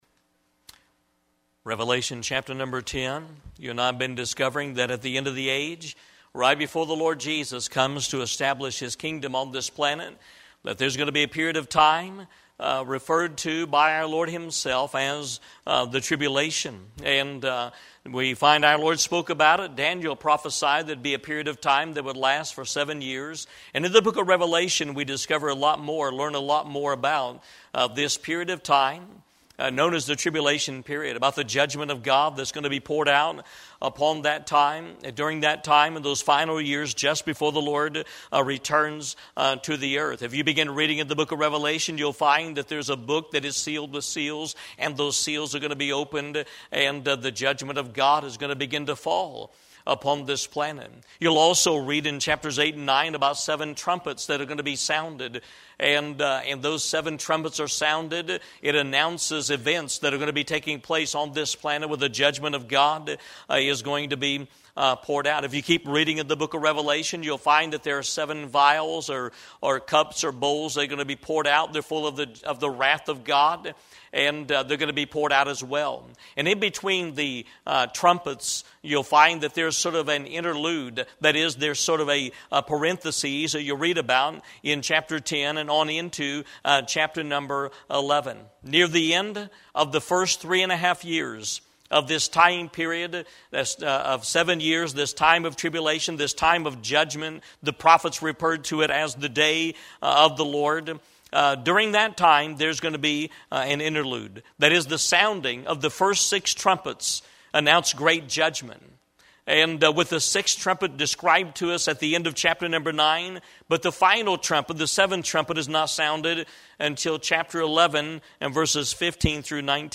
Eastern Gate Baptist Church - The Seven Thunders of the Tribulation 1